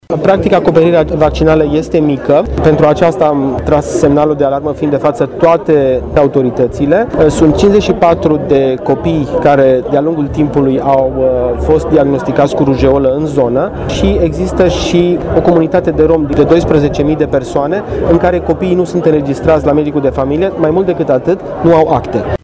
Aflat la Săcele, cu prilejul redeschiderii spitalului din localitate, ministrul Sănătății, Florian Bodog, a tras un semnal de alarmă privind acoperirea vaccinală în zonă, care se situează sub 50 la sută.